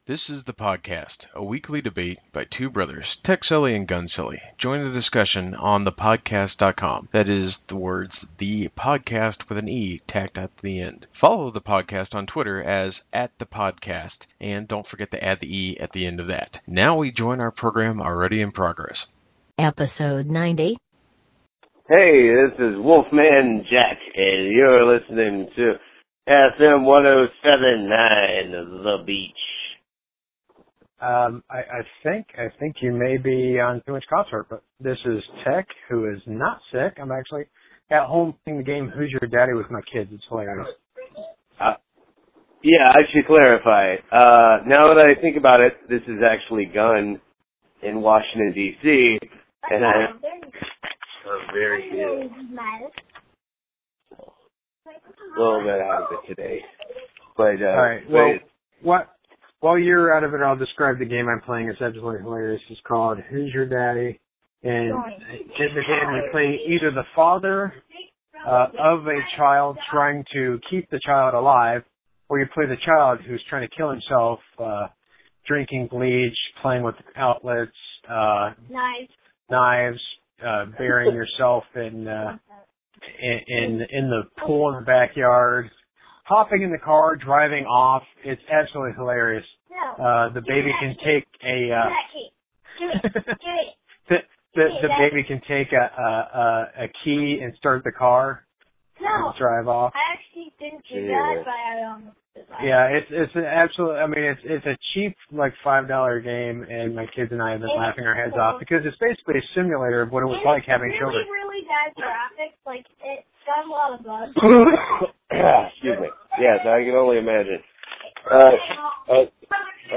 making a racket in the background